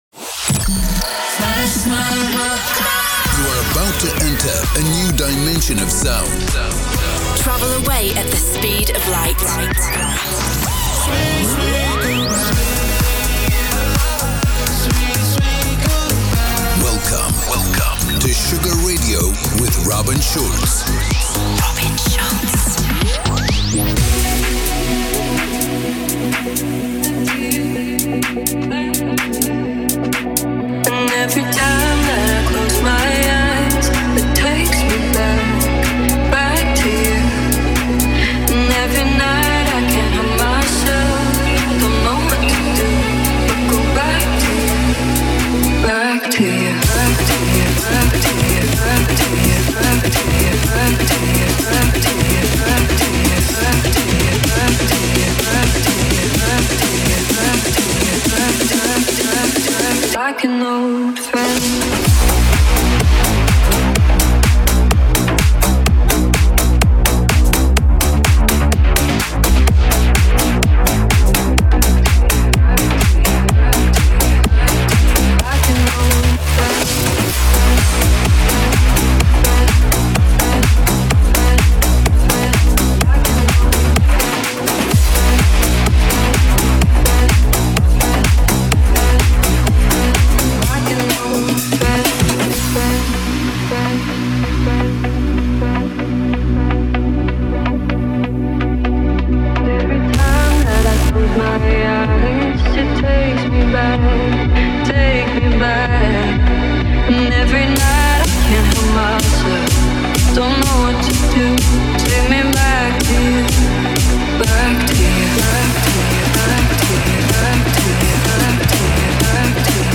Uma hora com o melhor Tropical House do músico